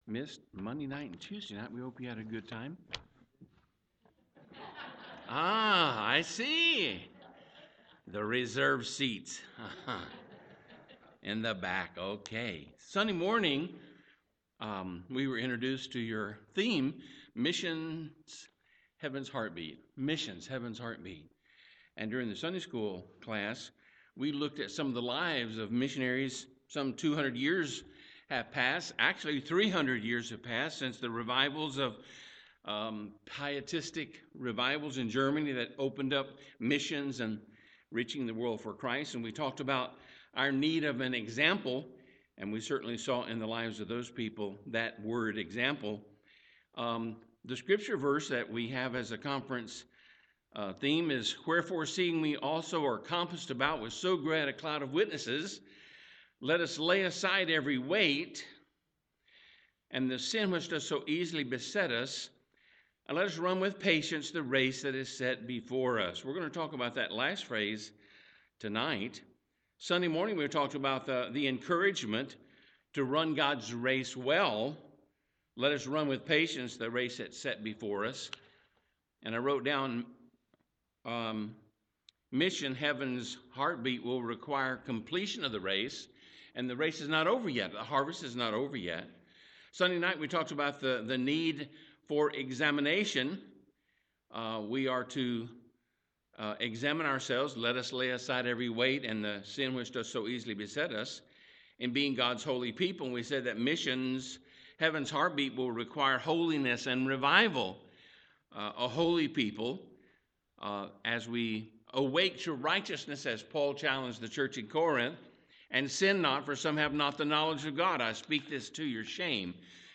Missions Conference PM
Sermon